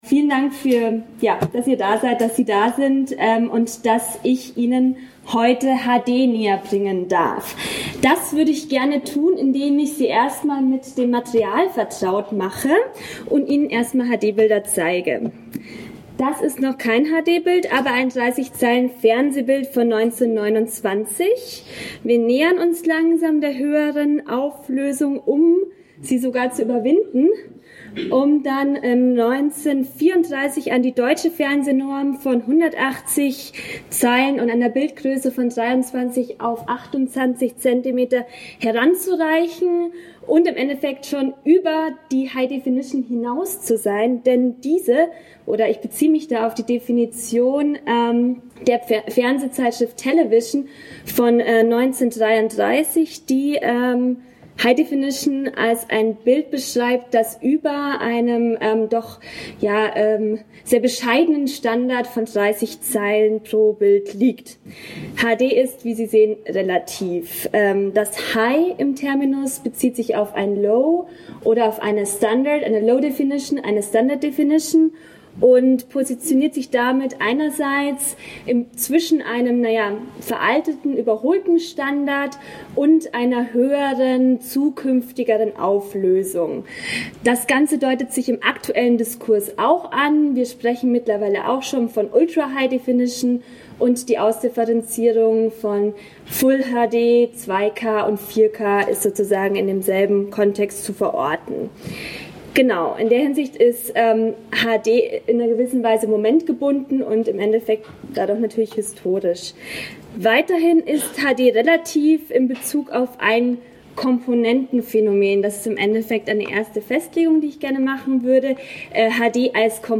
Affektion in Hochauflösung Abstract Dieser Vortrag beschäftigt sich mit High Definition (HD) – hochaufgelösten, digitalen Kinobildern – einerseits als ästhetisches Phänomen, jedoch auch als mediales Format.